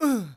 人声采集素材